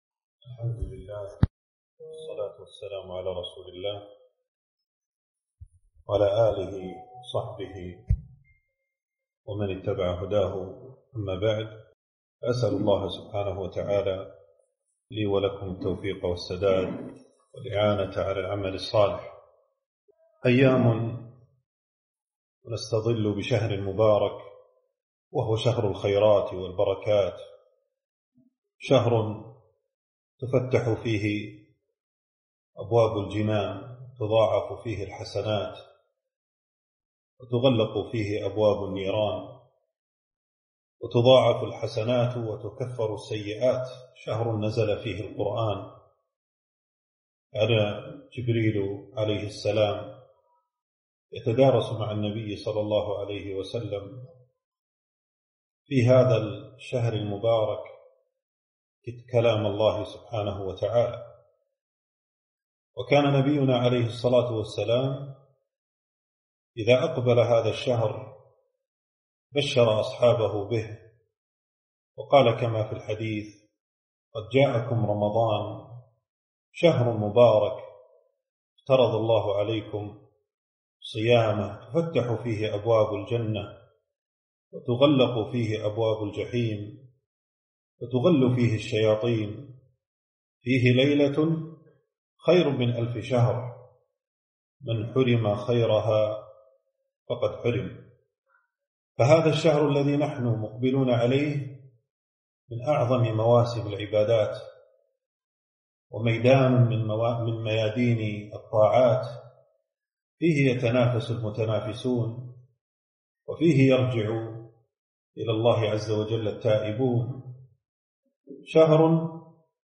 محاضرة- حال أهل الإيمان في رمضان